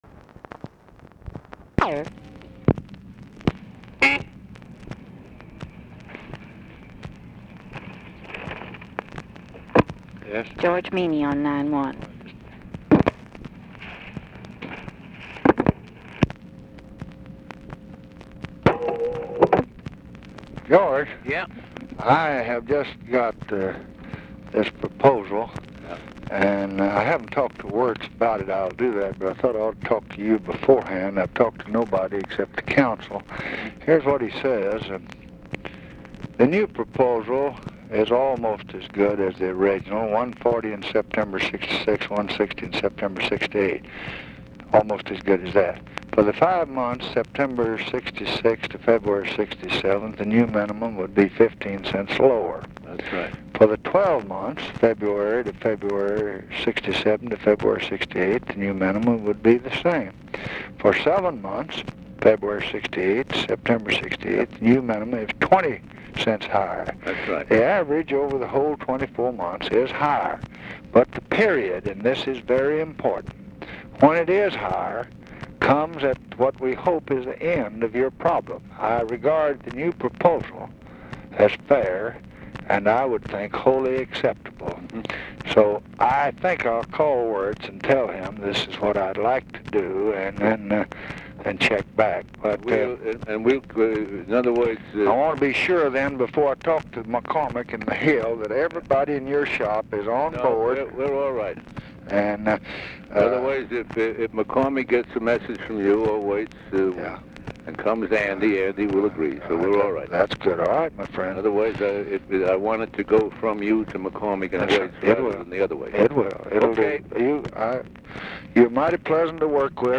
Conversation with GEORGE MEANY, March 9, 1966
Secret White House Tapes